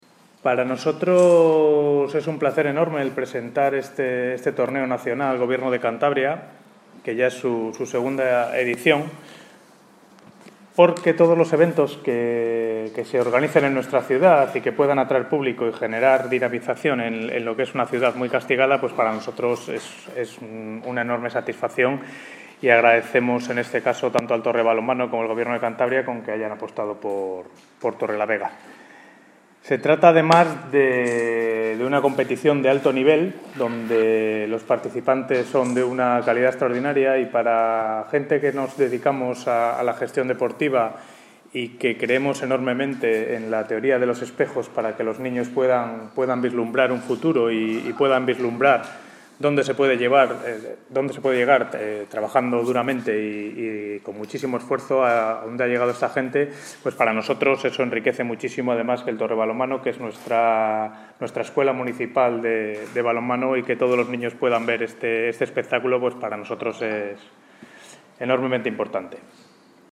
Presentación del Torneo Nacional de Balonmano
Jes?s S?nchez, concejal de Deportes